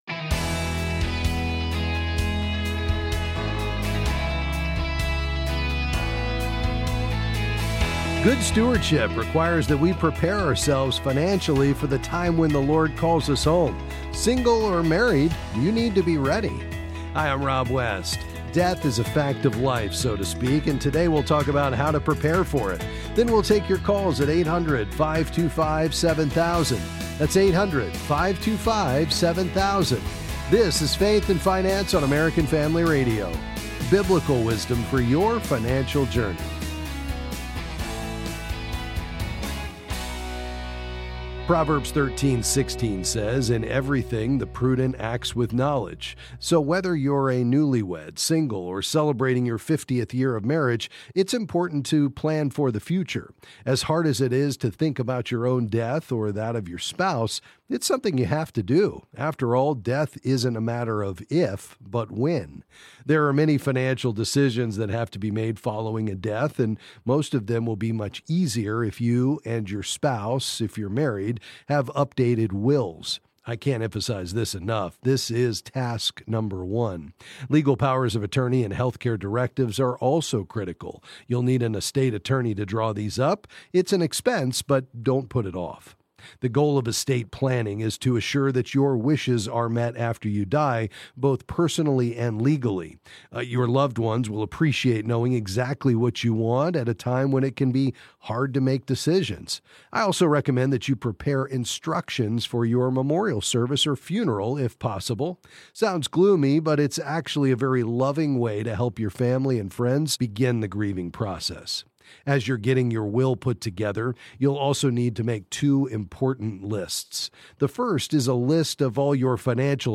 Then he answers your questions on a variety of financial topics.